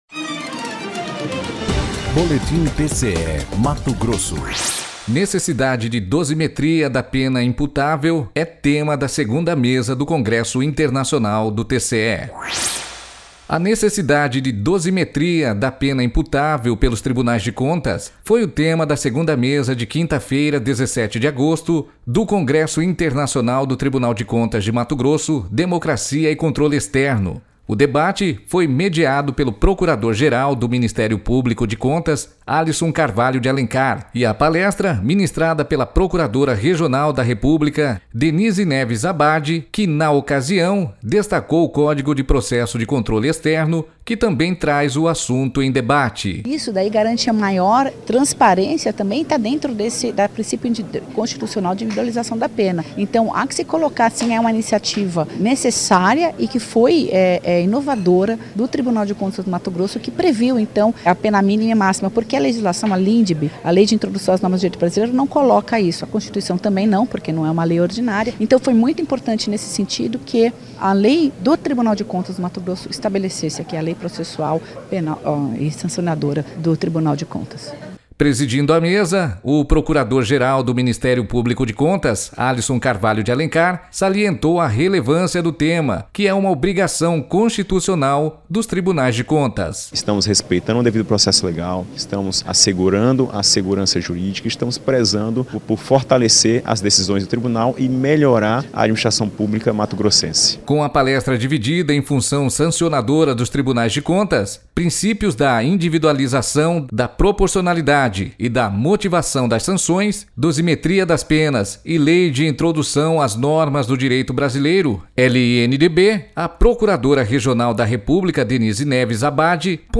Sonora: Denise Neves Abade - procuradora regional da República
Sonora: Alisson Carvalho de Alencar - procurador-geral do MPC-MT